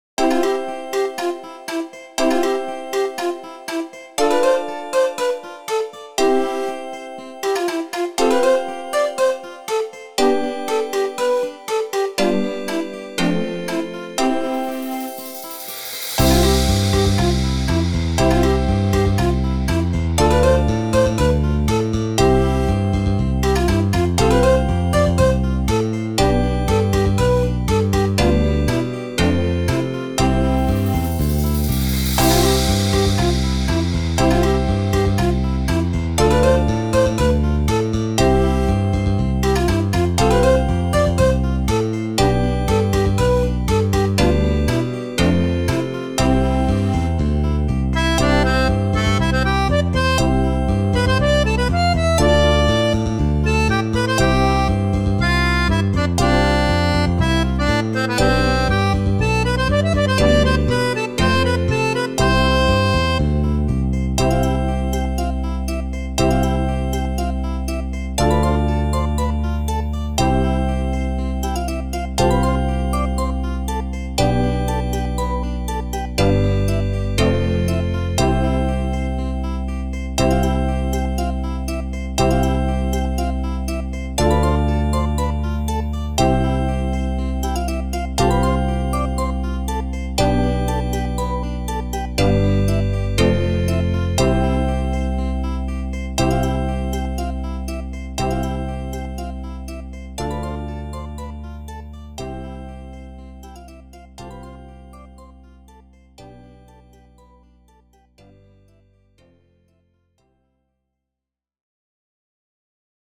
あかるい かわいい